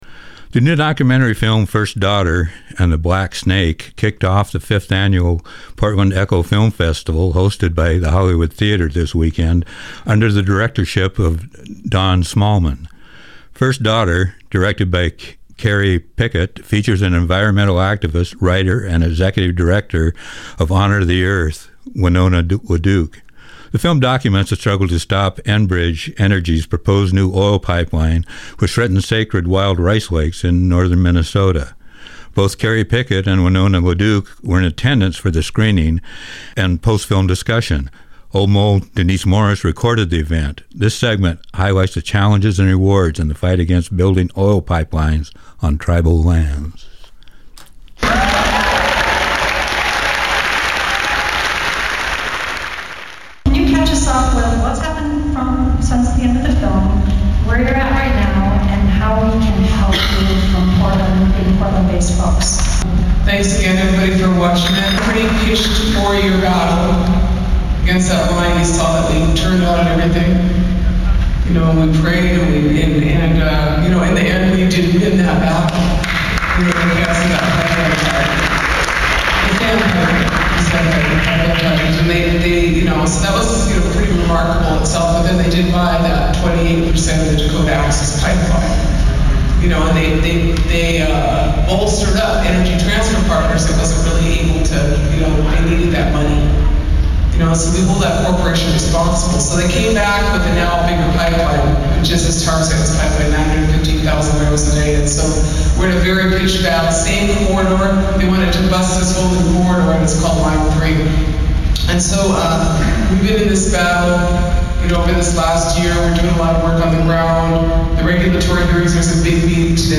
A recording of the discussion featuring Winona LaDuke following the showing of First Daughter and the Black Snake, a documentary film about a proposed oil pipe line set to invade sacred Native American ground.